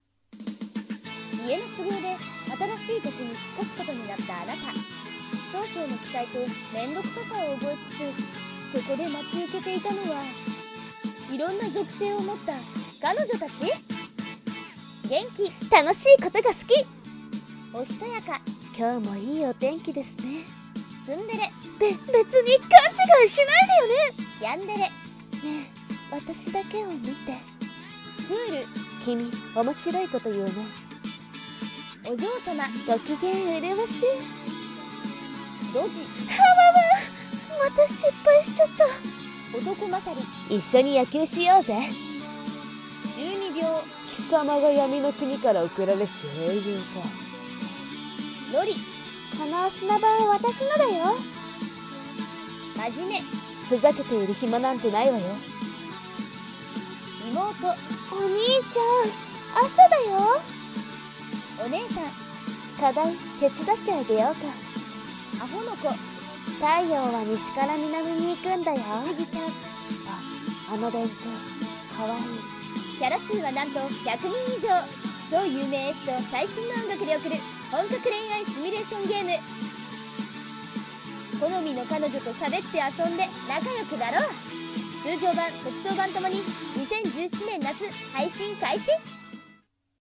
【ゲームCM風声劇】属性彼女っ♪